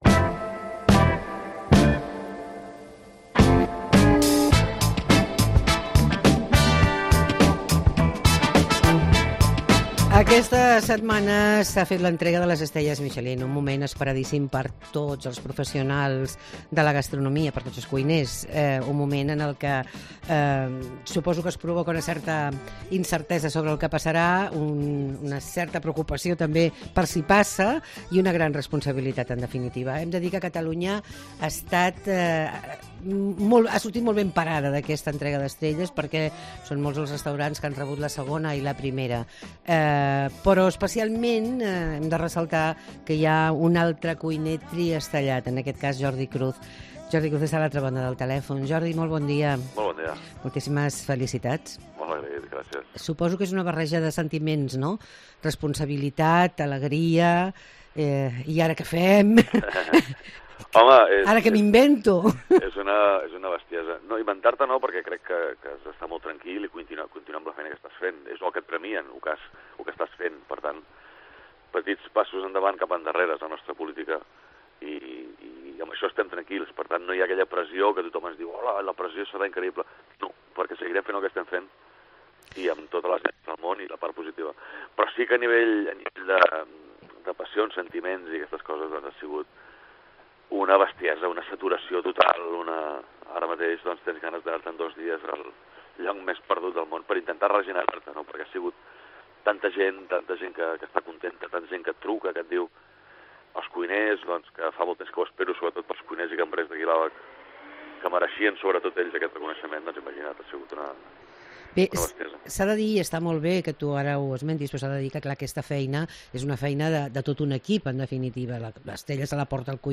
Entrevista a Jordi Cruz